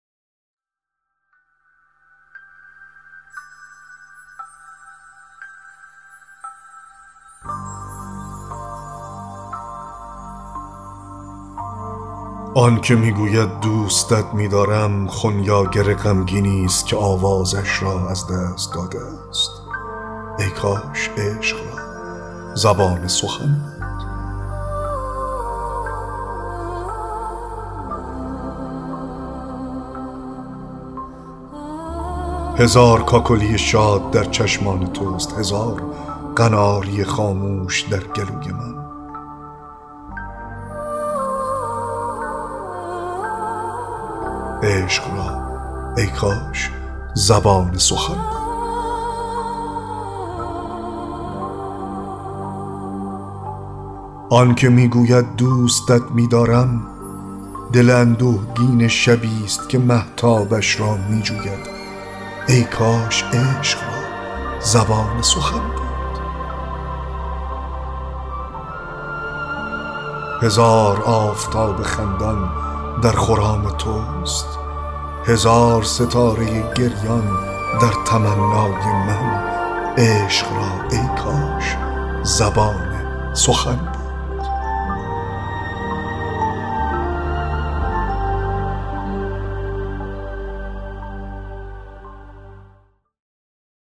3- دکلمه شعر عاشقانه (آن که می گوید دوستت دارم…)